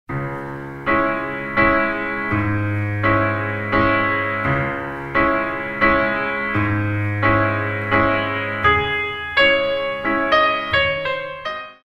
Music is mainly selected from classical ballet repertoire.